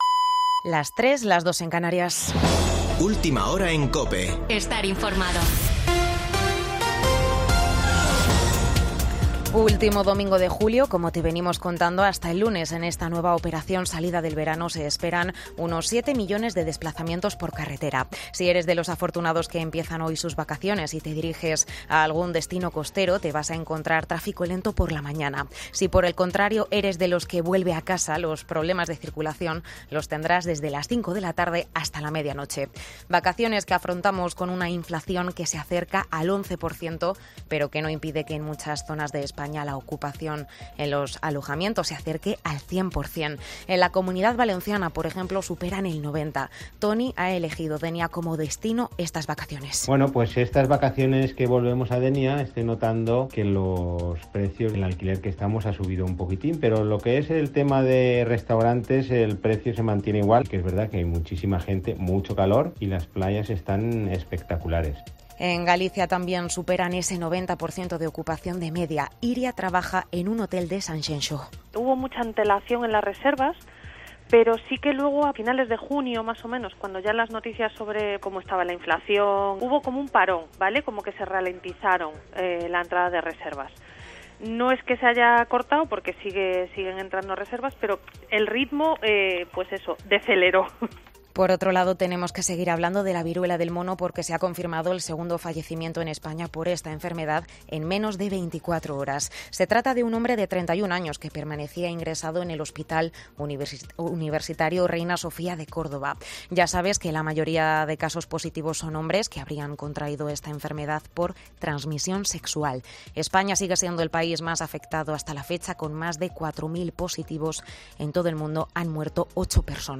Boletín de noticias de COPE del 31 de julio de 2022 a las 03:00 horas